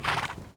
snort.wav